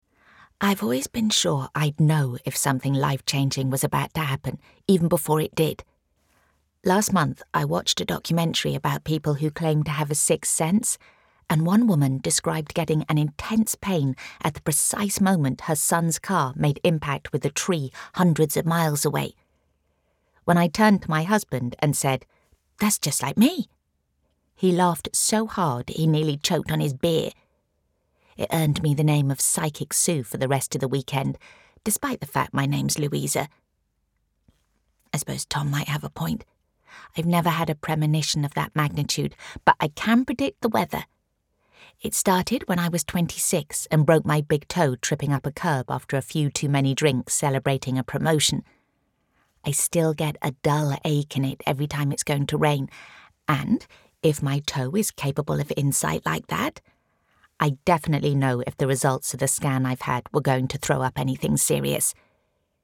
I have a naturally husky, sexy mid tone.
Native RP, Native Cornish and Fantasy